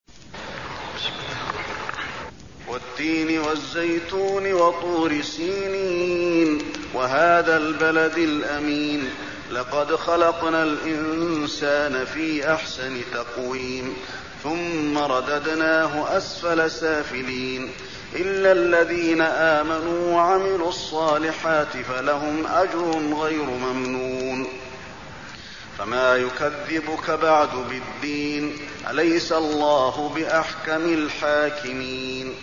المكان: المسجد النبوي التين